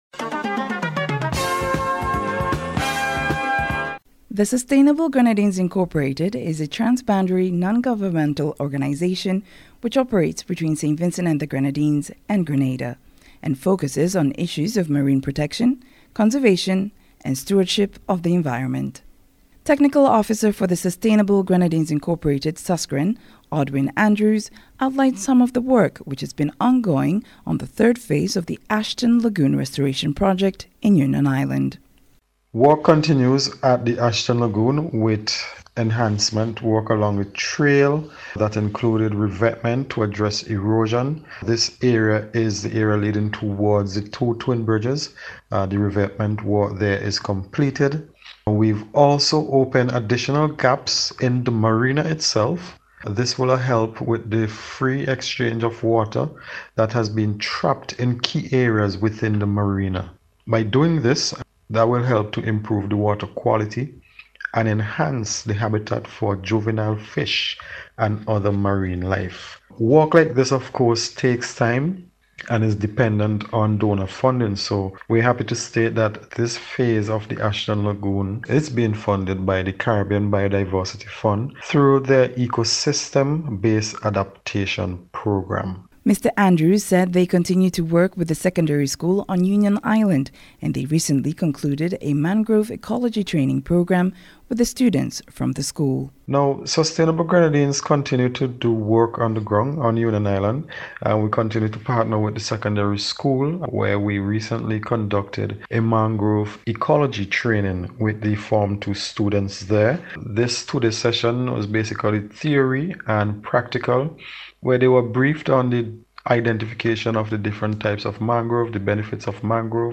Special Report